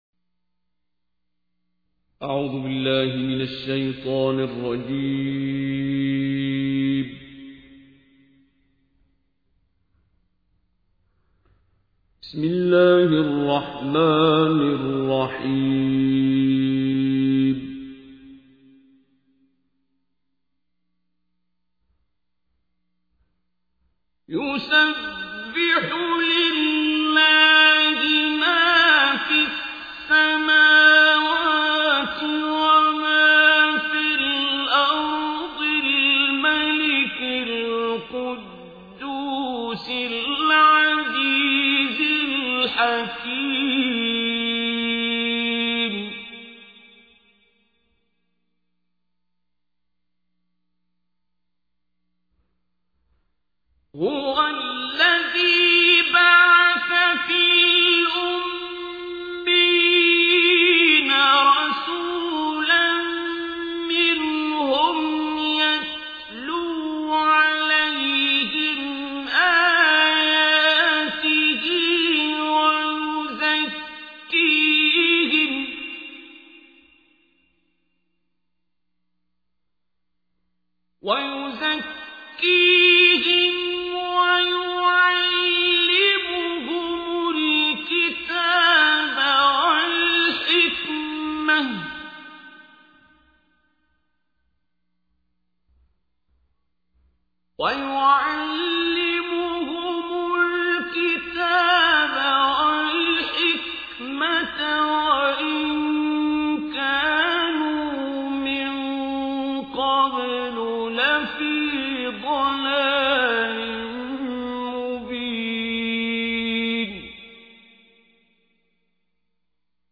تحميل : 62. سورة الجمعة / القارئ عبد الباسط عبد الصمد / القرآن الكريم / موقع يا حسين